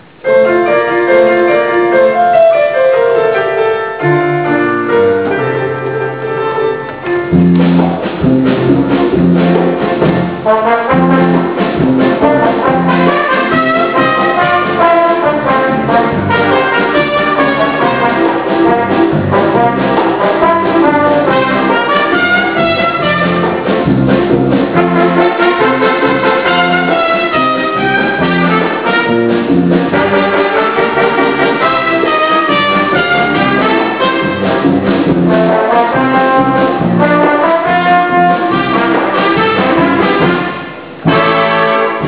丸亀ユニオンジャズオーケストラの荒井注さん追悼コンサート「銭はなくても全員集合」は、２０００年６月１０日、約１８０人のお客様が来場され無事終了いたしました。
なお、この曲はジャズということになっている。